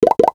NOTIFICATION_Pop_12_mono.wav